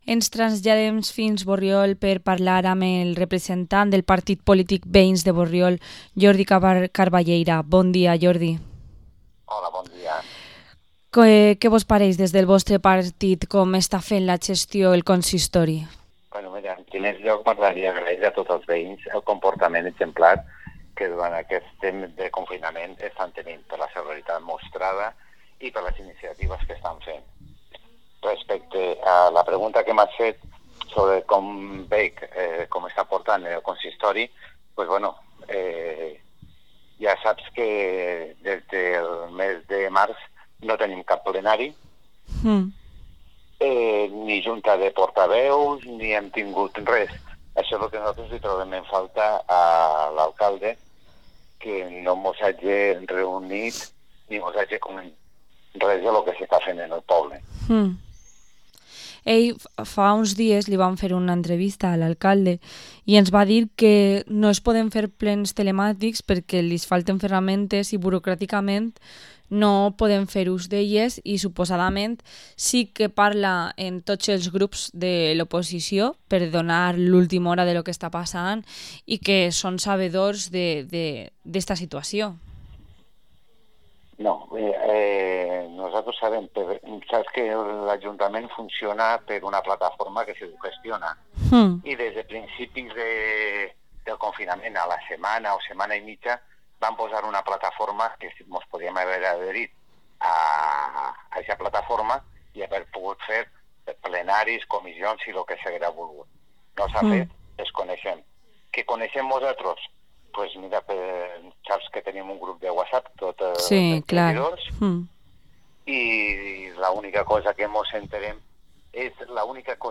Entrevista al concejal de Veïns de Borriol, Jordi Carballeira